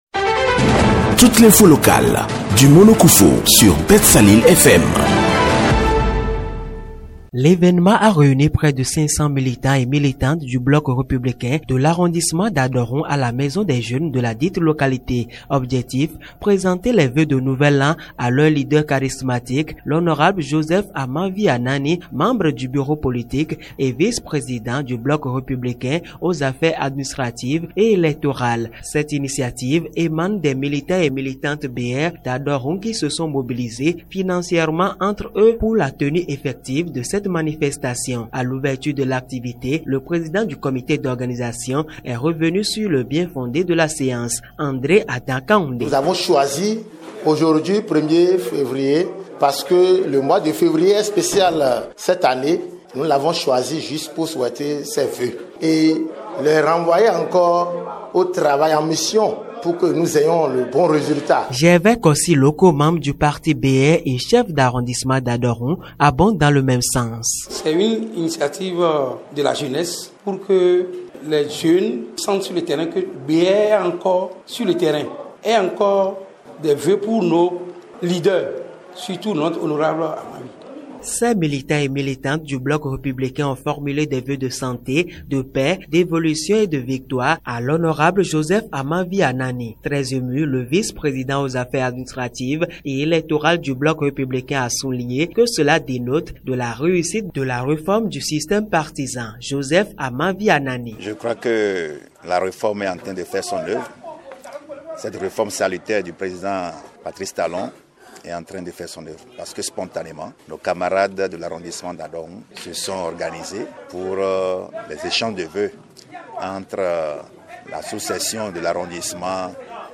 Cette activité dénommée ” Journée des militantes et militants du BR de l’arrondissement d’Adohoun” s’est déroulée le samedi 1er février 2025 à la maison des jeunes de la localité avec une grande mobilisation.